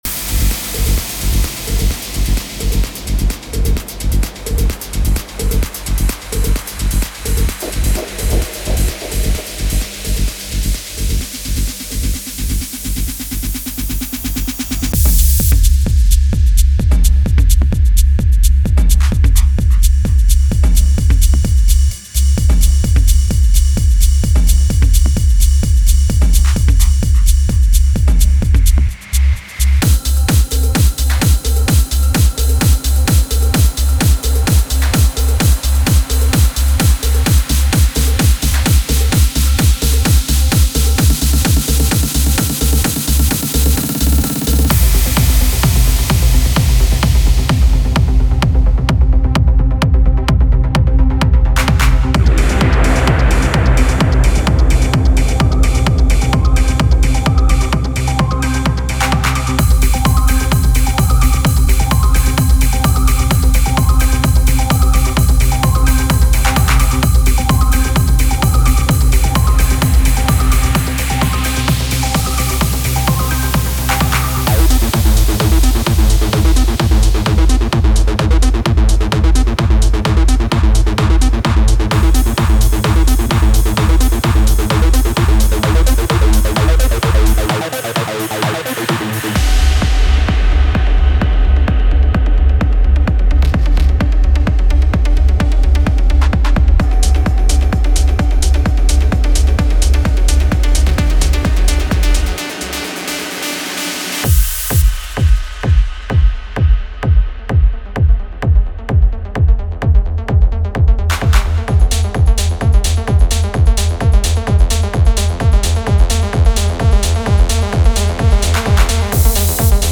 Type: Samples
Melodic Techno Trance
We build this pack with the aim to offer fresh ideas and all the tools you need for create a complete, pumpin' techno track: from rumble and bass loops to percussions, drones and fx sounds, this pack deliver top-notch content to power your music.